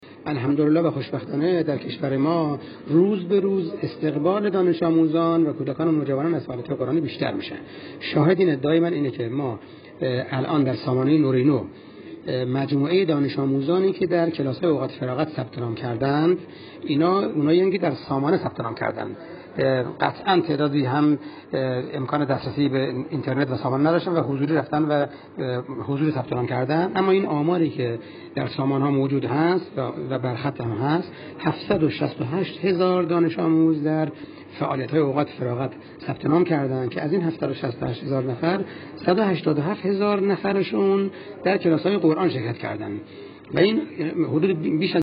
میکائیل باقری، مدیرکل قرآن، عترت و نماز وزارت آموزش و پرورش در گفت‌وگو با ایکنا با اشاره به فعالیت پایگاه‌های اوقات فراغت دانش‌آموزی با رشته‌های مختلف قرآنی، فرهنگی، ورزشی، مهدویت و ...، اظهار کرد: در کشور حدود 30 هزار پایگاه اوقات فراغت برای غنی‌سازی اوقات فراغت دانش‌آموزان با رشته‌های مختلف فعال است.